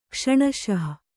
♪ kṣaṇaśah